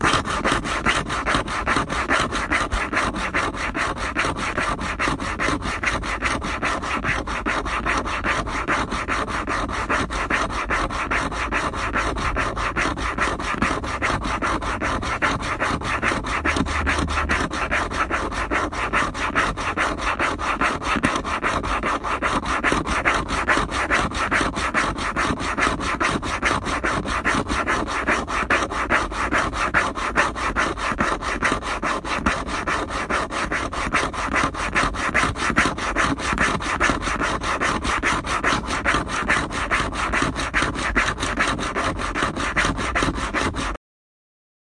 Metal cutting
描述：metal cutting sound effect was recorded with an iPhone 7s in an open space and edited with reaper.
声道立体声